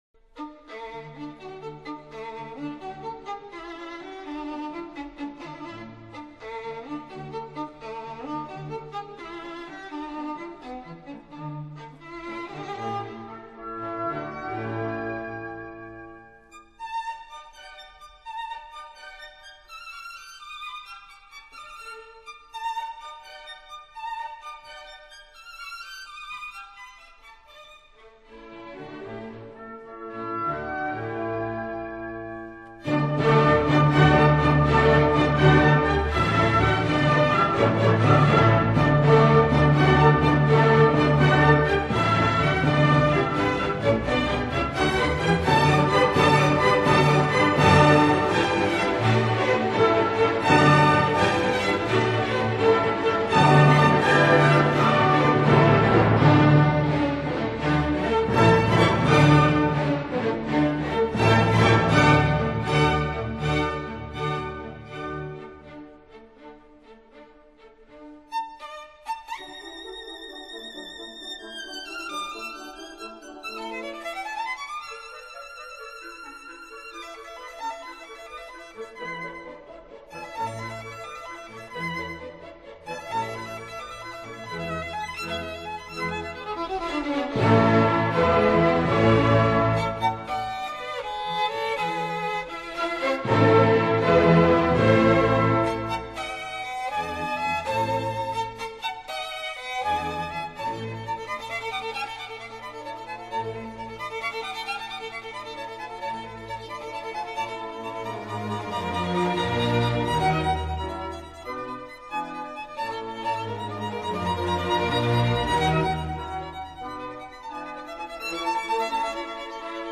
这里是贝多芬D大调小提琴协奏曲—— 贝多芬D大调小提琴协奏曲 3、回旋曲：快板